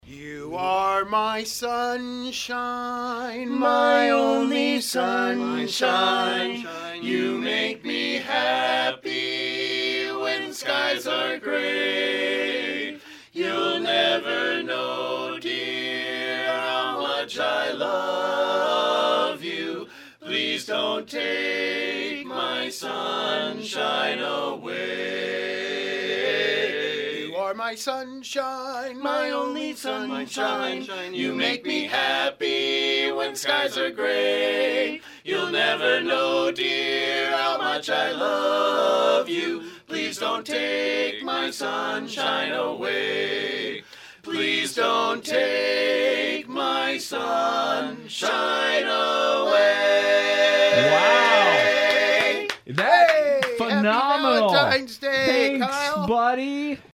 mens-chorus-valentine-1.mp3